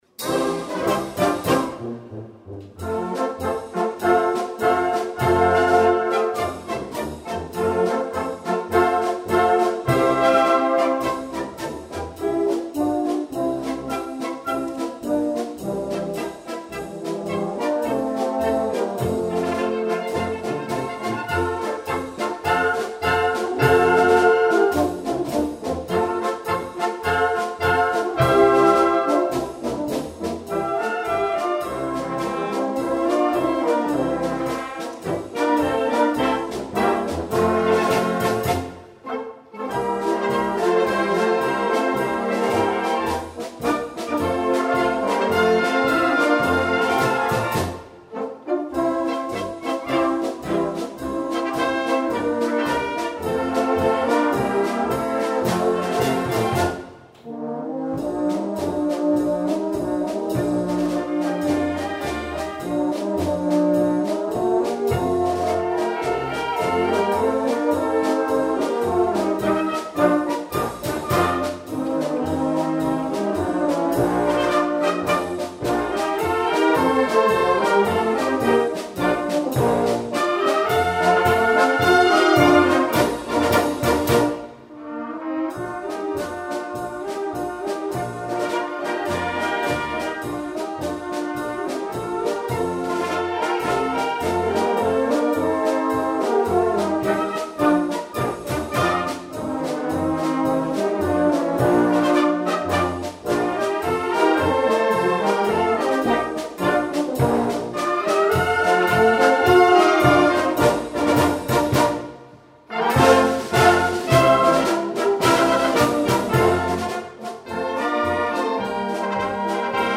Rundfunk – Studioaufnahme in Salzburg